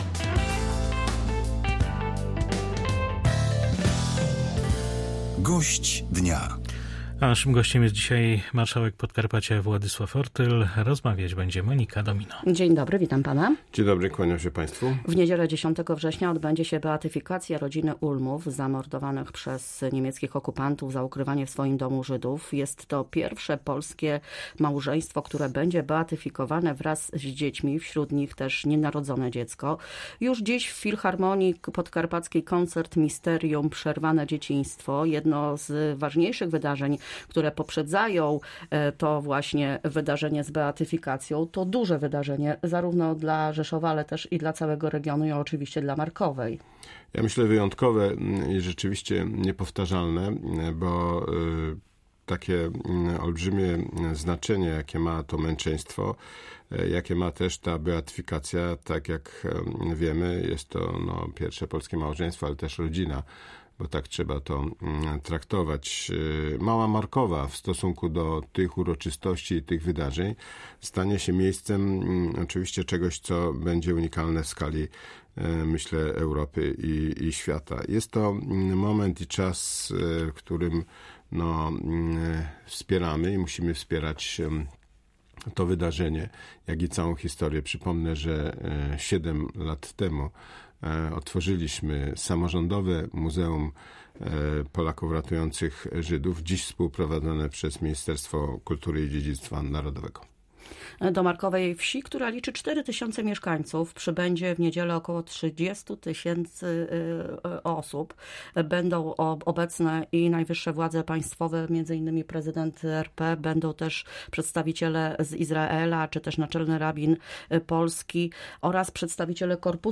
Gość Polskiego Radia Rzeszów Marszałek Władysław Ortyl podkreśla, że niedzielne wydarzenie to ogromne przedsięwzięcie logistyczne i też unikatowe pod względem charakteru.
08_09_gosc_dnia.mp3